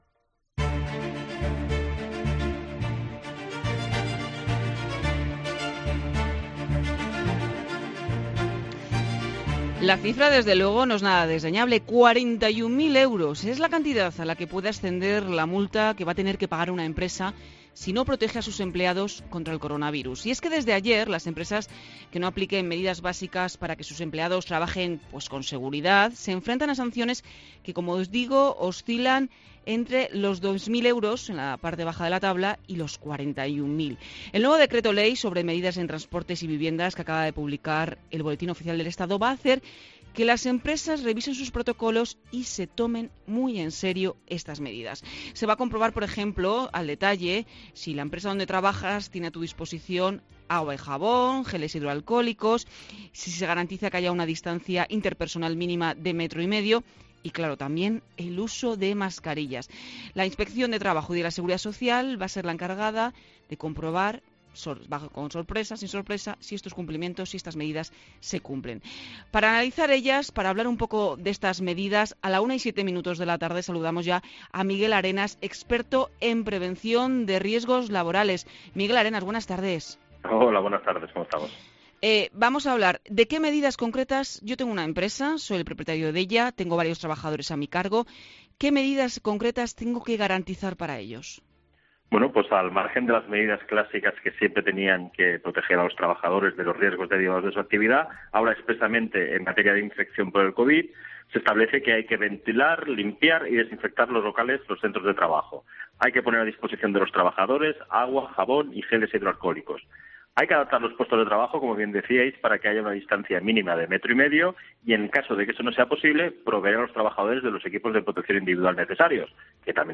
experto en prevención de riesgos laborales y profesor de la Universitat Oberta de Catalunya ha repasado estas medidas en Mediodía COPE.